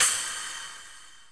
Soundfile 3: a standard 16bit stereo WAVE file, but with a long header.